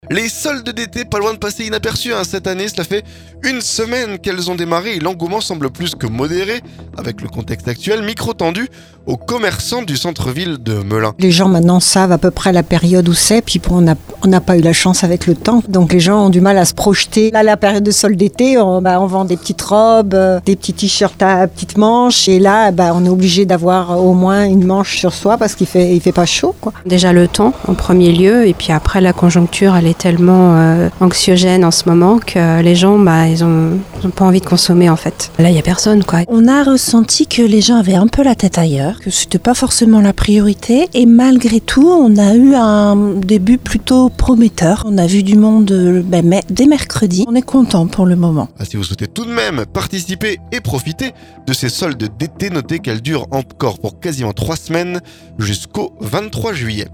Cela fait une semaine qu'elles ont démarré et l'engouement semble plus que modéré. Micro tendu aux commerçants du centre-ville de Melun.